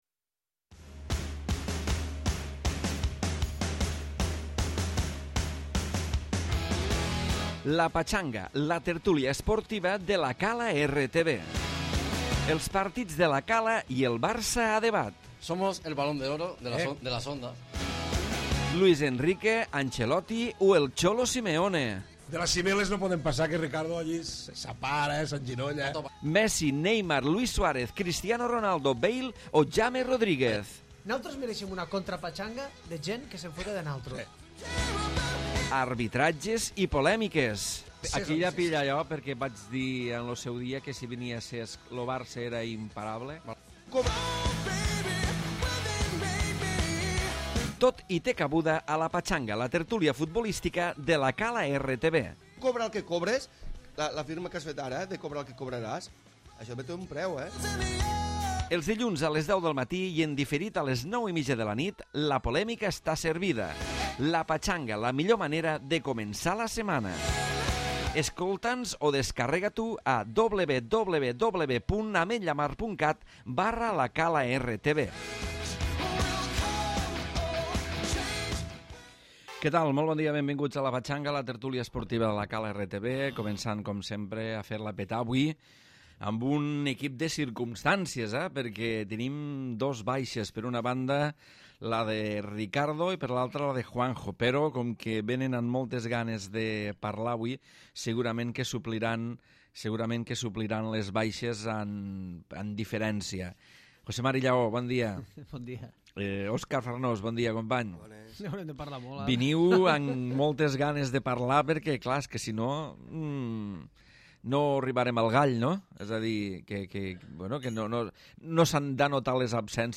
Tertúlia futbolística d'actualitat.